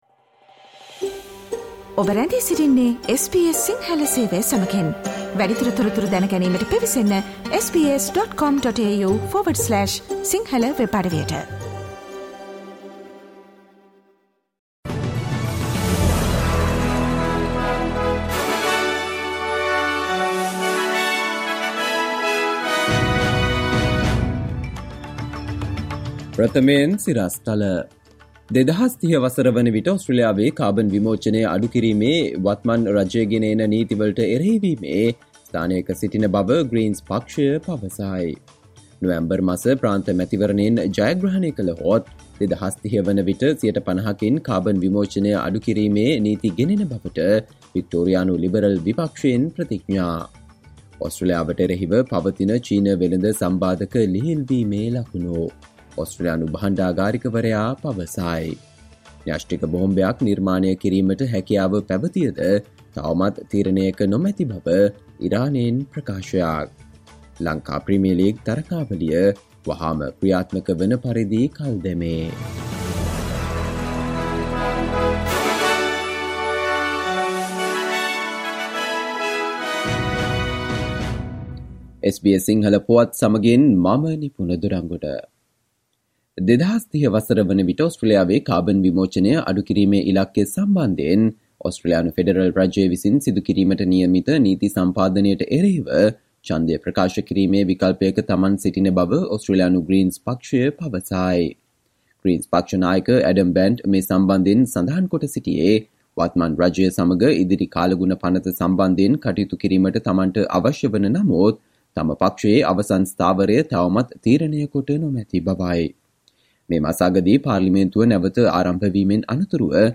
සවන්දෙන්න 2022 ජූලි 18 වන සඳුදා SBS සිංහල ගුවන්විදුලියේ ප්‍රවෘත්ති ප්‍රකාශයට...